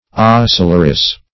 Ostleress \Ost"ler*ess\, n. A female ostler.